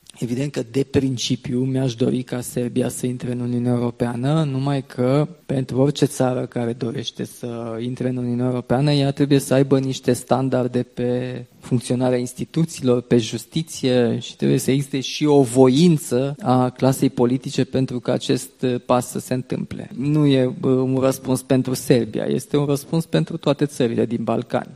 Au putut apoi adresa întrebări și jurnaliștii. Una s-a referit la susținerea Serbiei în parcursul ei  european.